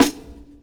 Medicated Snare 29.wav